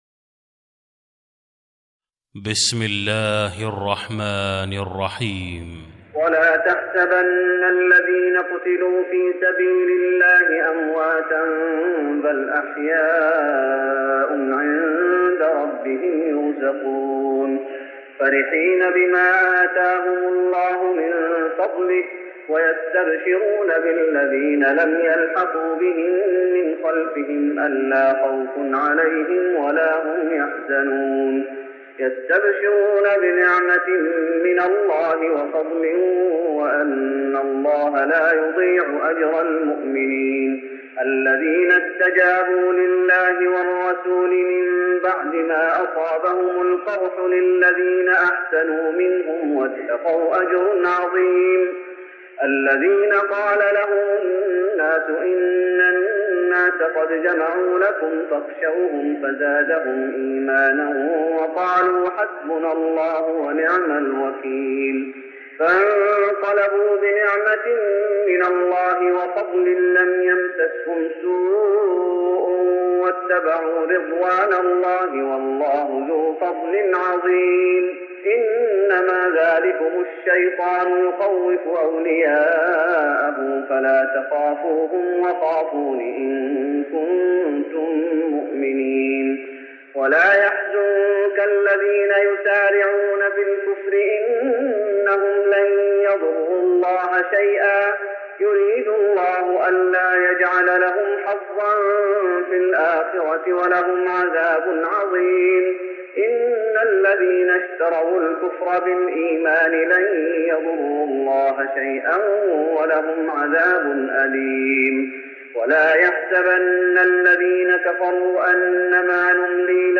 تراويح رمضان 1414هـ من سورة آل عمران (169-200) Taraweeh Ramadan 1414H from Surah Aal-i-Imraan > تراويح الشيخ محمد أيوب بالنبوي 1414 🕌 > التراويح - تلاوات الحرمين